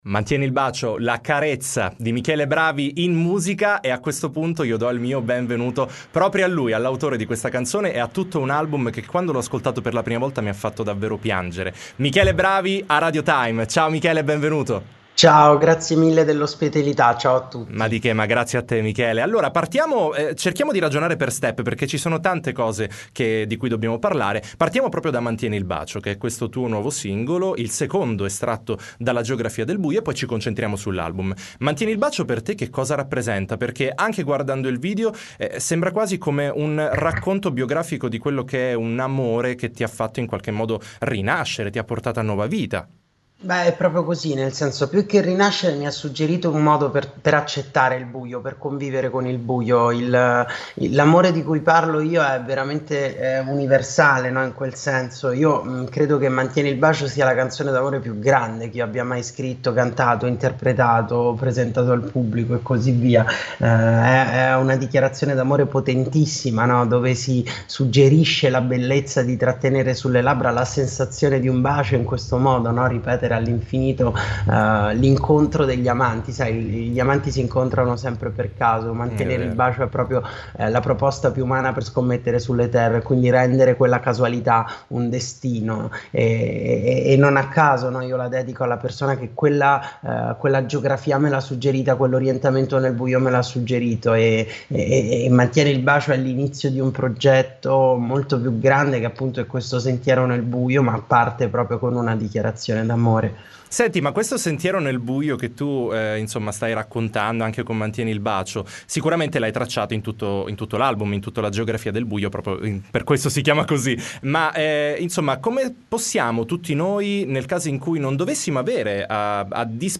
T.I. Intervista Michele Bravi